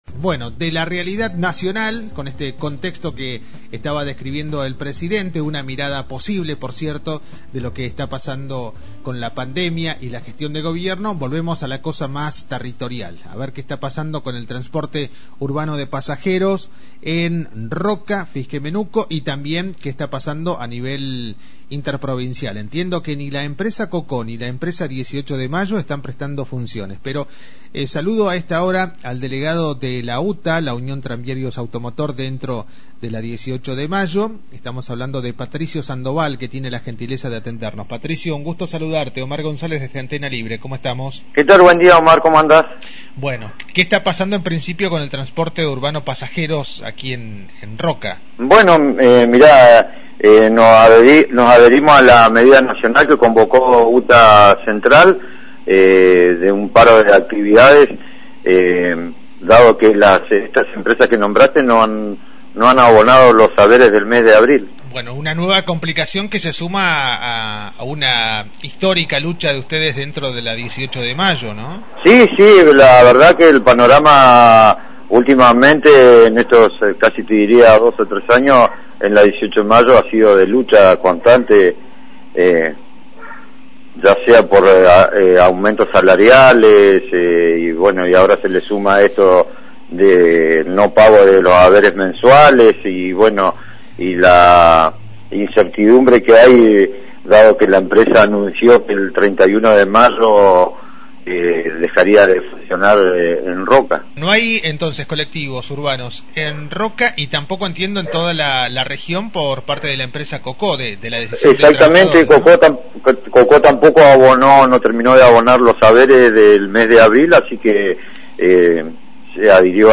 informó a los micrófonos de Antena Libre que el paro será desde hoy hasta mañana a las 13.30 según la medida propuesta por el gremio a nivel nacional.